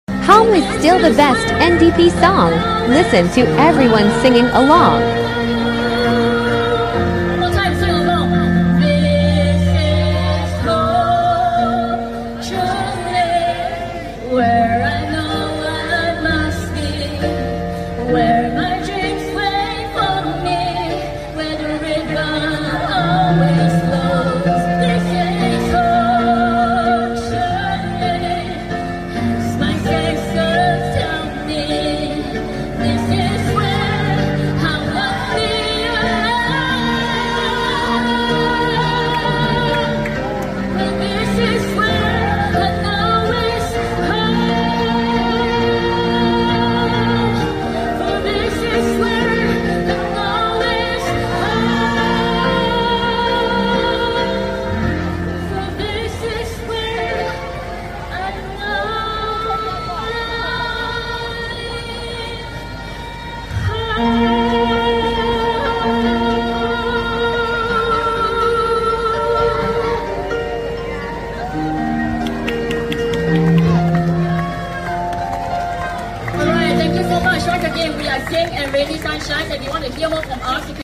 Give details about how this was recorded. Thank you everyone for being such wonderful audience at the Nee Soon East National Day Carnival 2024!